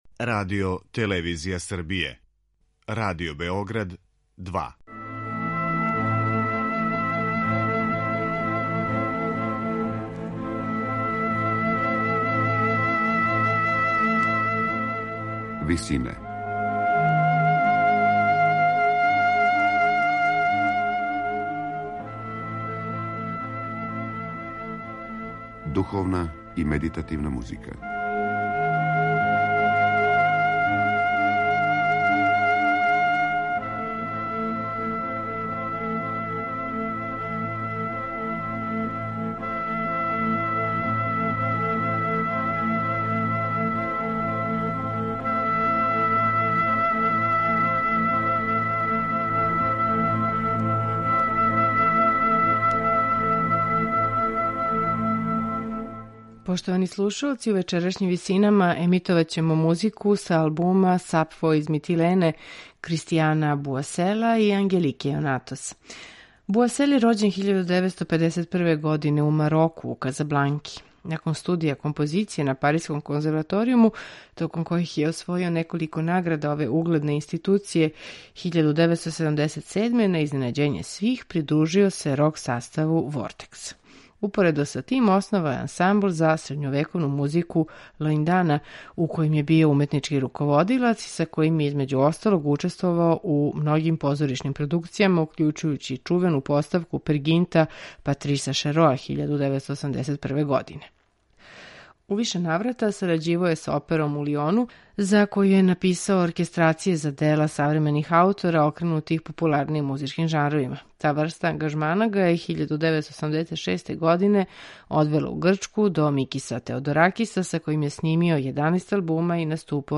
медитативне и духовне композиције
грчке певачице